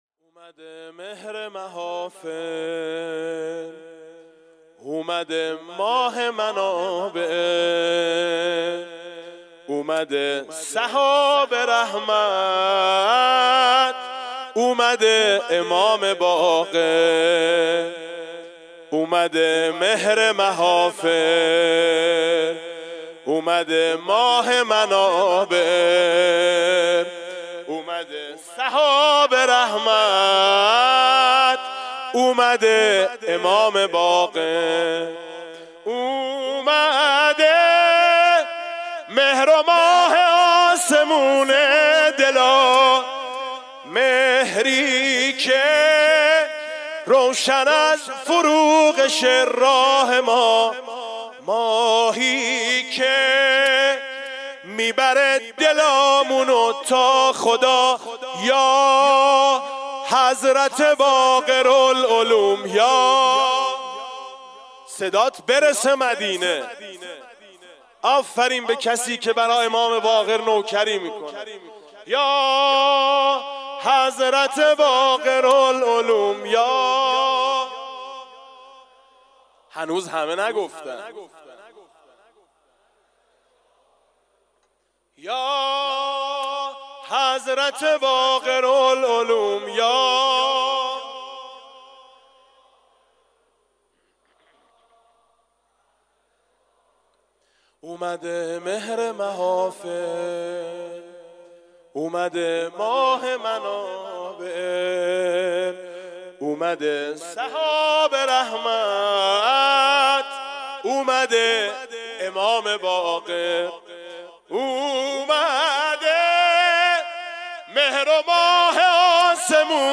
مدح-اومده-مهر-و-ماه-آسمون-دلا